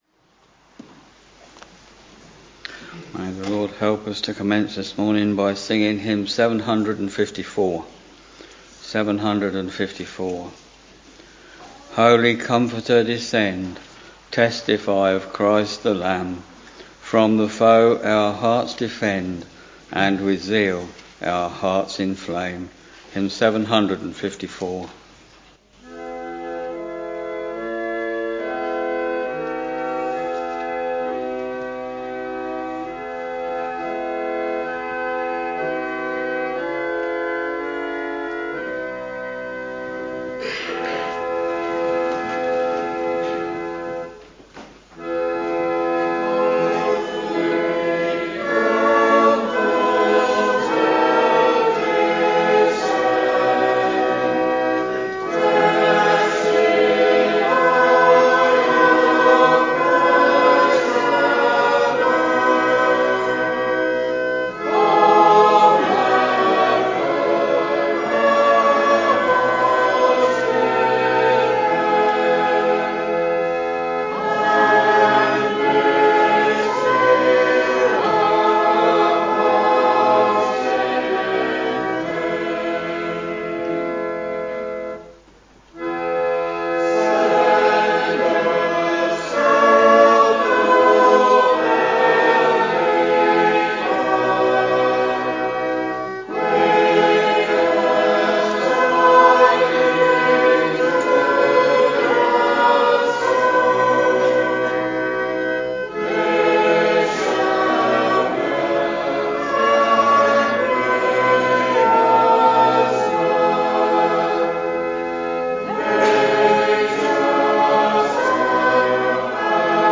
Morning Service Preacher